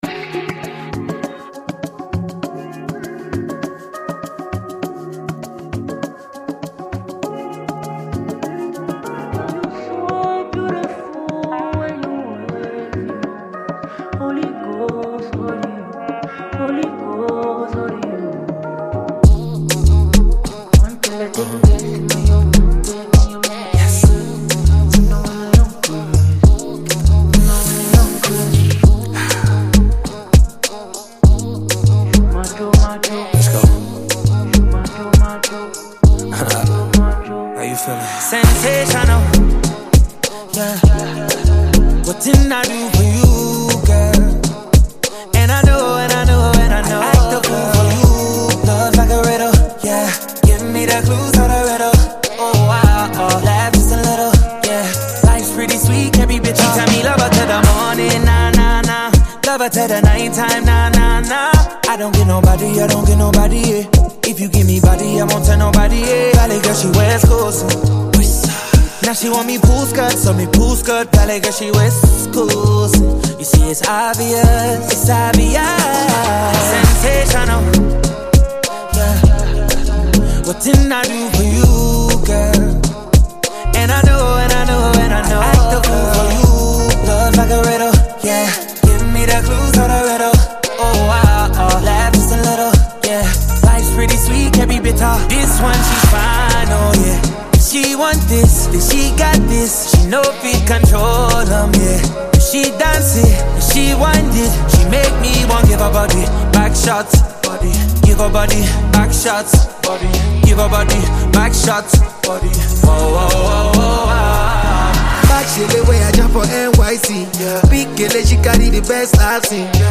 R&B, pop, and smooth beats